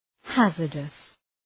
Προφορά
{‘hæzərdəs}